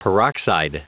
Prononciation du mot peroxide en anglais (fichier audio)
Prononciation du mot : peroxide